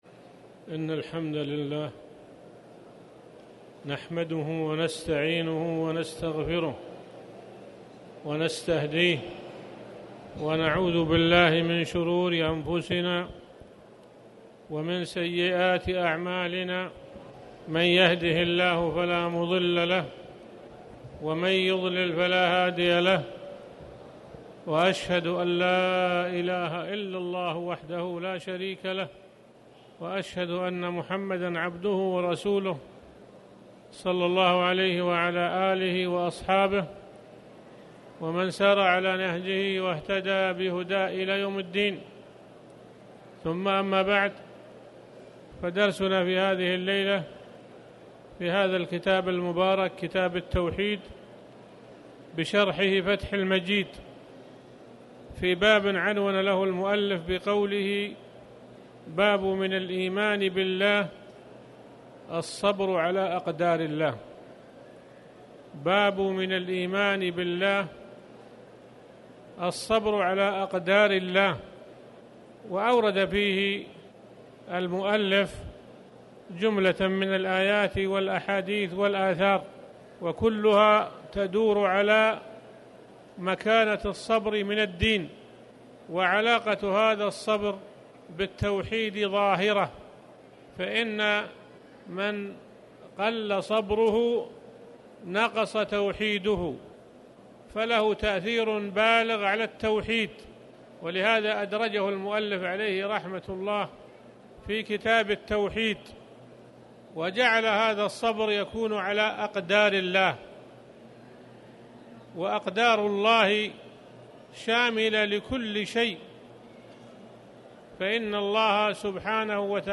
تاريخ النشر ١١ صفر ١٤٣٩ هـ المكان: المسجد الحرام الشيخ